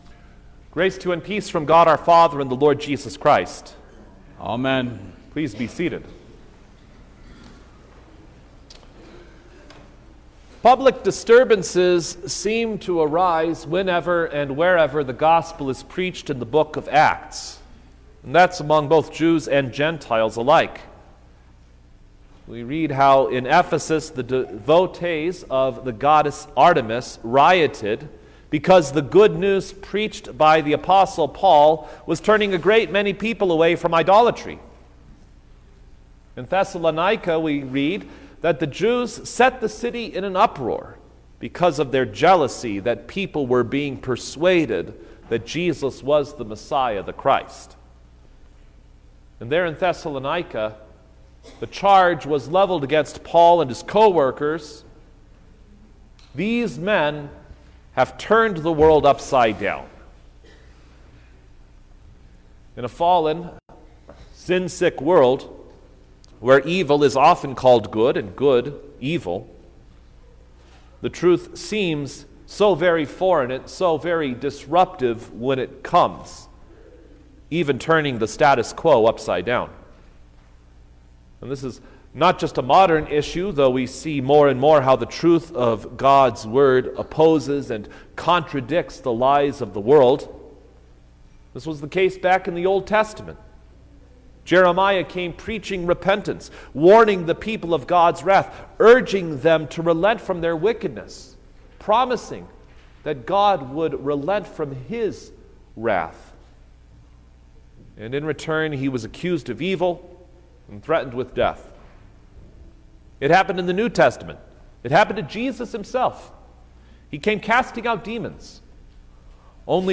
March-3_2024_Third-Sunday-in-Lent_Sermon-Stereo.mp3